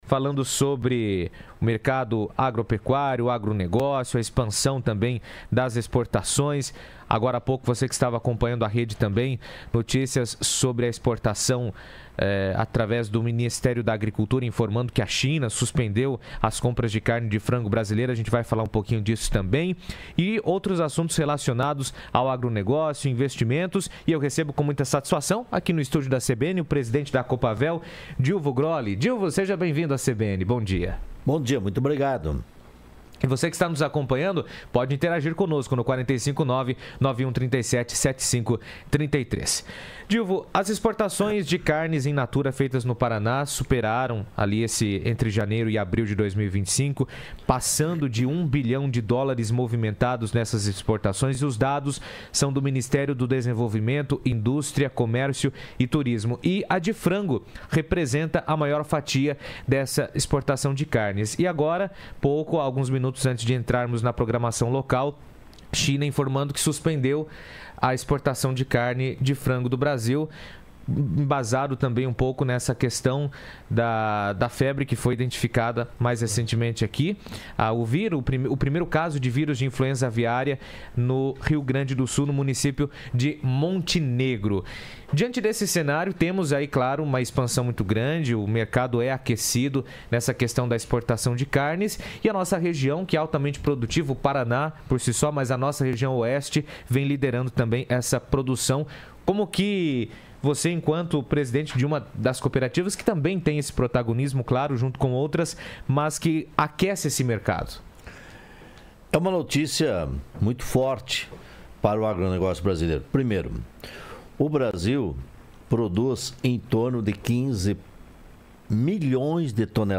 esteve no estúdio na CBN trazendo um panorama das exportações de carne do Paraná e falou dos investimentos no setor de psicultura.
Na entrevista